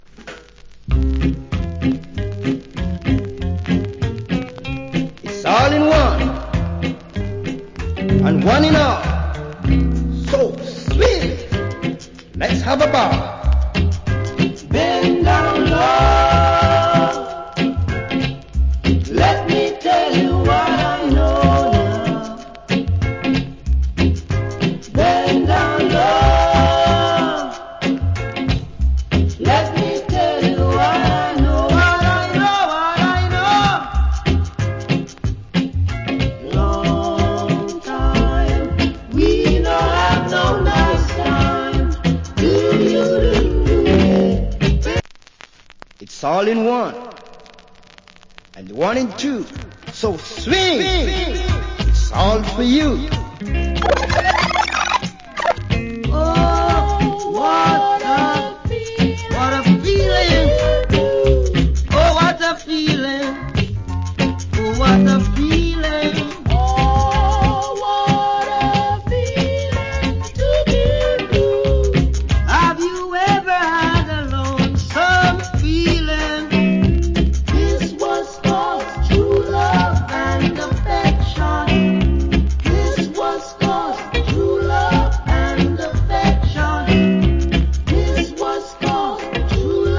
Great Roots Rock Vocal. Medley.